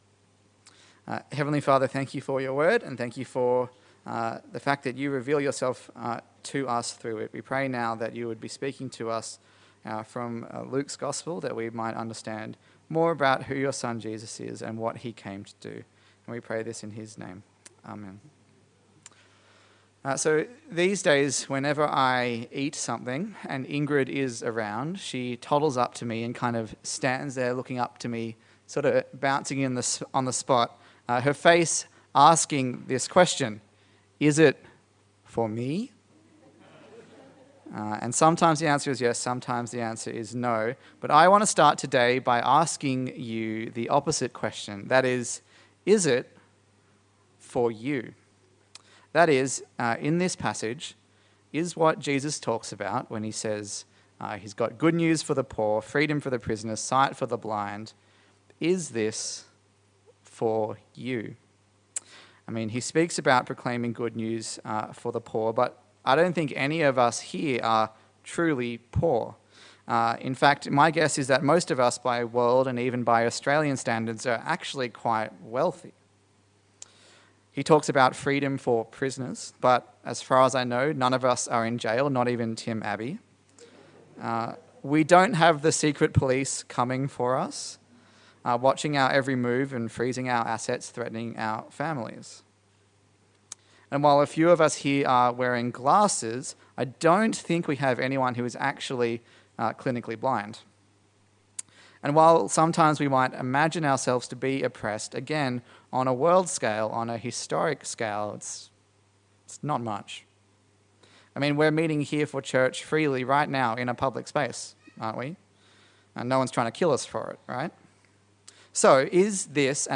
Luke Passage: Luke 4:14-30 Service Type: Sunday Service